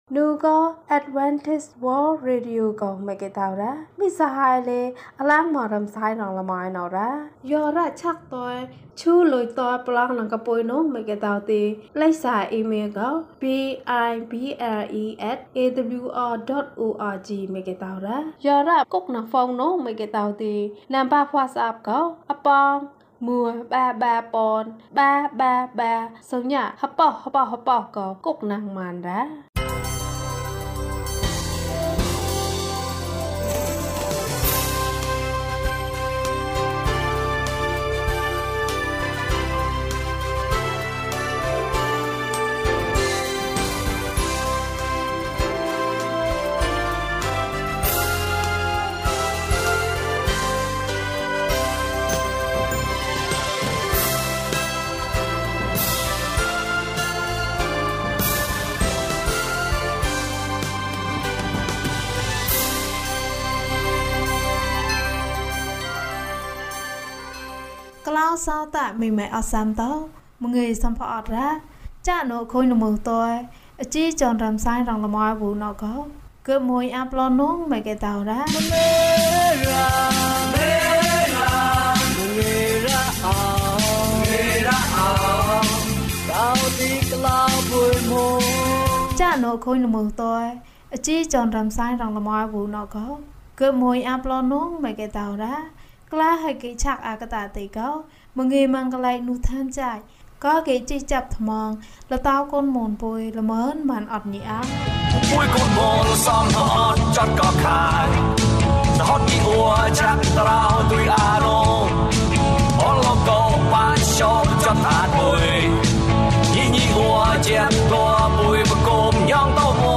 ယေရှုခရစ်၏အသက်တာ။၀၁ ကျန်းမာခြင်းအကြောင်းအရာ။ ဓမ္မသီချင်း။ တရားဒေသနာ။